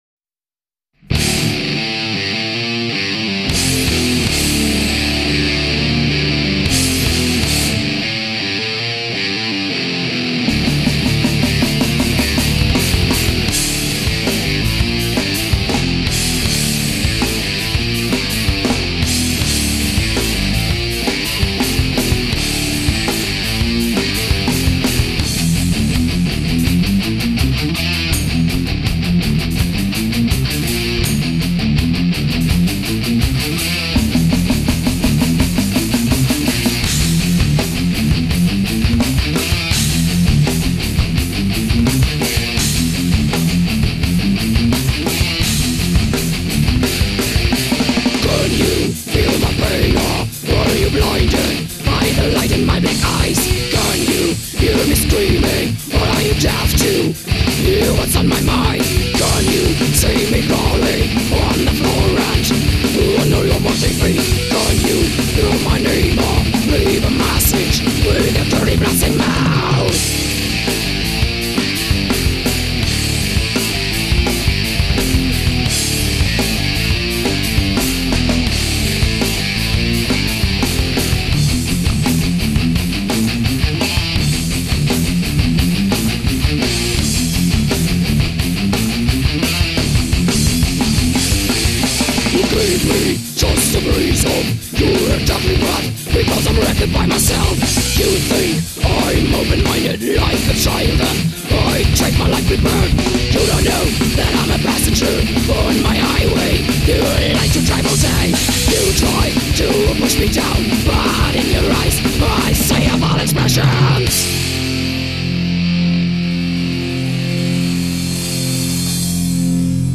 Vocals
Guitars
Bass
Drums